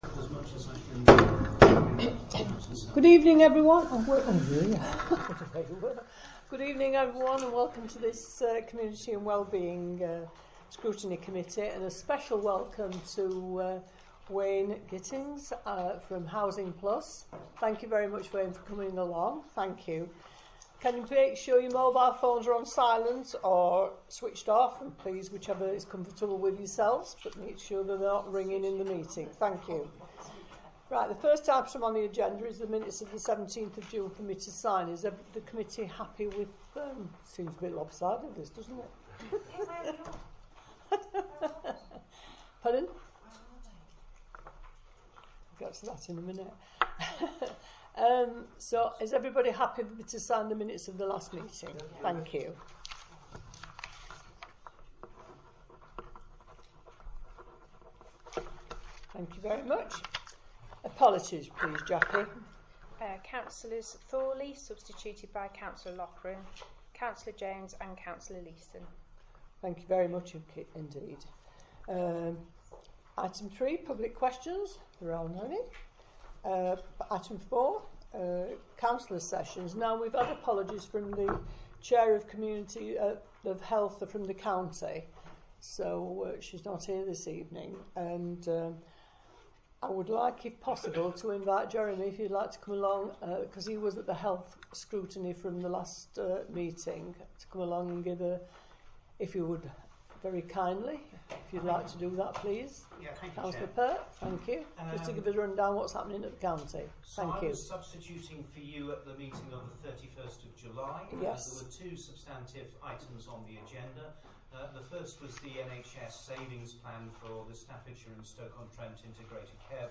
Location: Craddock Room, Civic Centre, Riverside, Stafford